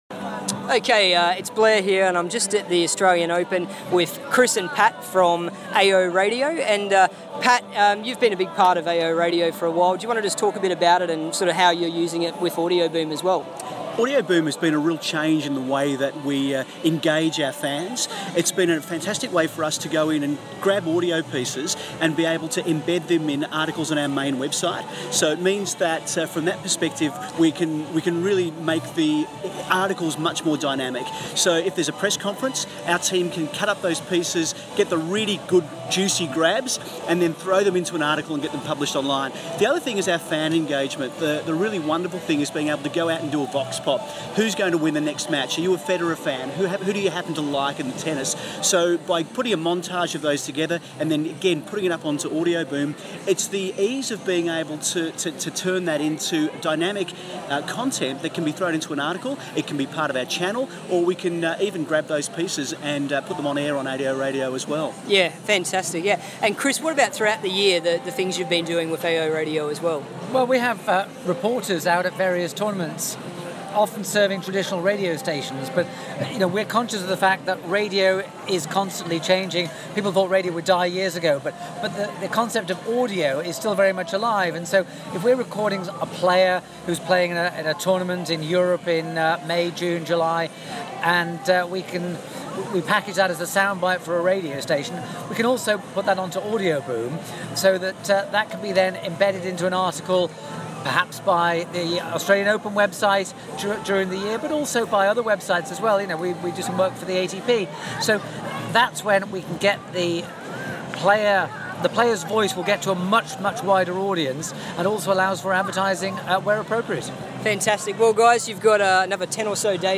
Australian Open Radio Chat